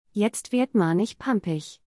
(Please note: the audio pronunciations in this post are in a “Standard German” accent, so they don’t sound exactly as they would coming from a real Berliner.)